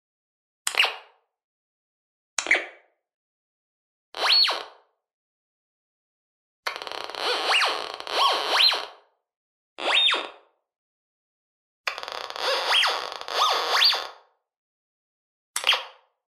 Звуки металлоискателя
На этой странице собраны звуки металлоискателей разных типов: от монотонных сигналов старых моделей до современных многозональных тонов.